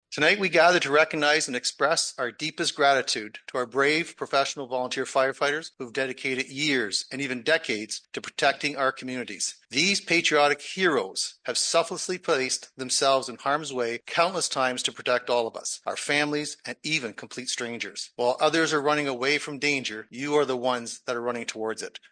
To kick off council this week, Fire Long-Term Services Awards were handed out to 11 outstanding individuals.
Mayor George Finch started the award presentation with some kind words for all the firefighters who have served and continue to serve South Huron and area.